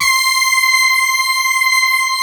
BRASS2 MAT.5.wav